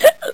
Hiccup Sound 2